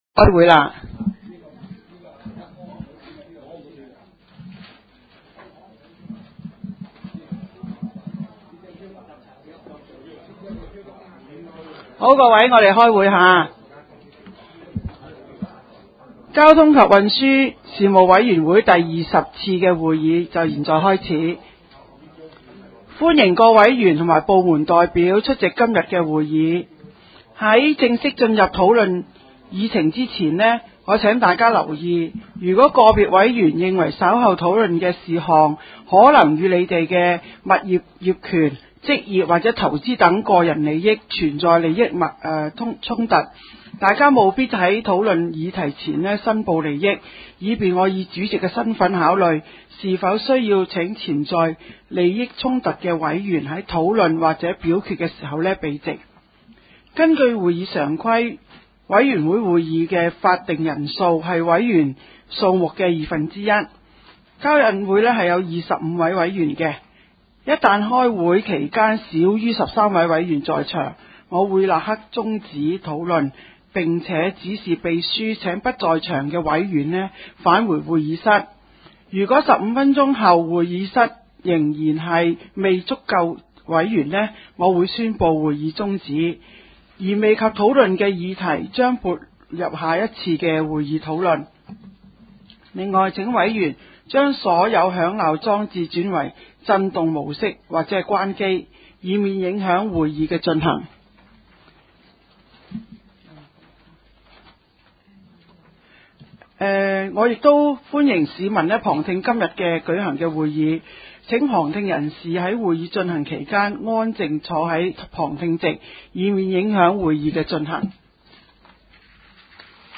九龍城民政事務處會議室